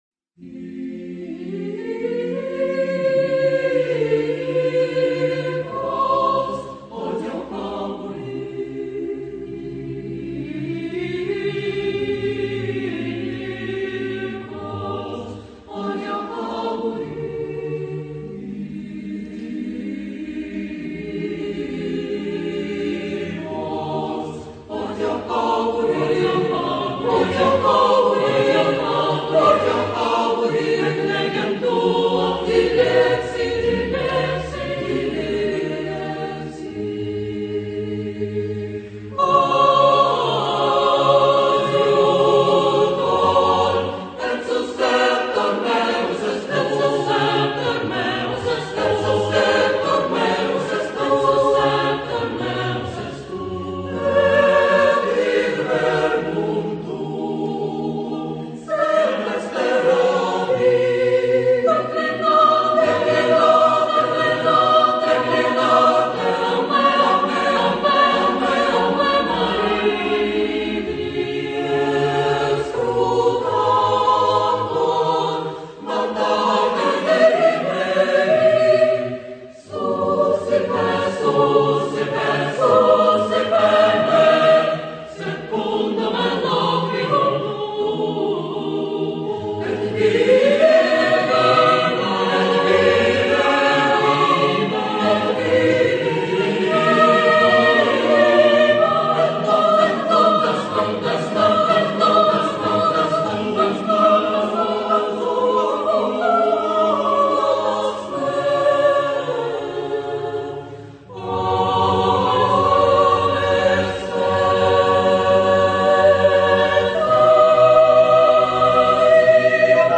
Roganova Youth Capella
Since then it has been growing and developing, and today is Gatchina’s town choir, one of the best amateur choir of the Leningrad region, well-known in Saint-Petersburg and Russia.
The core members of Capella are creative young people aged 15-40.